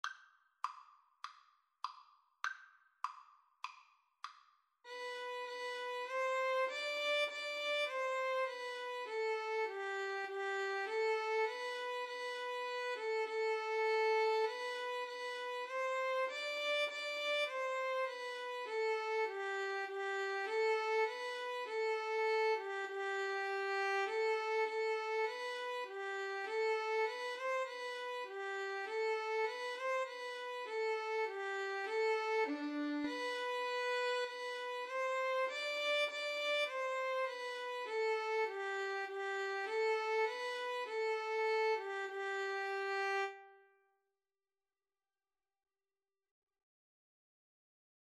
4/4 (View more 4/4 Music)
Classical (View more Classical Violin-Cello Duet Music)